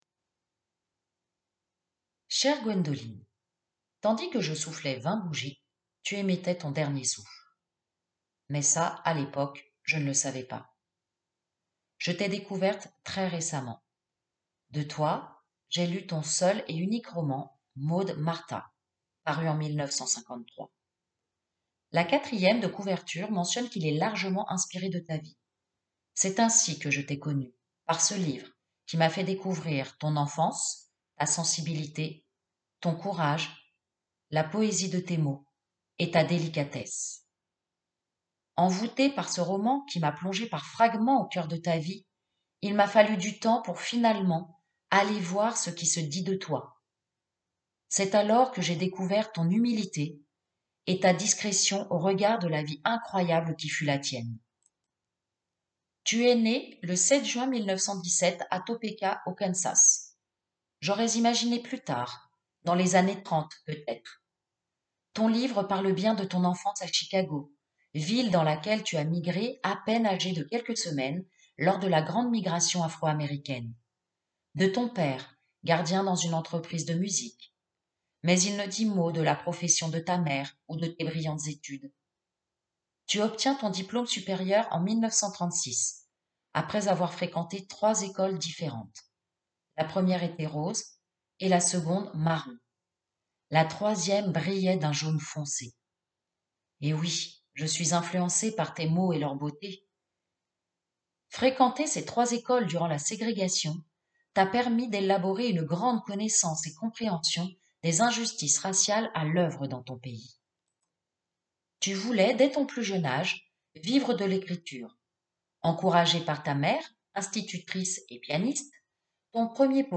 Version audio du portrait